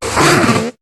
Cri de Marcacrin dans Pokémon HOME.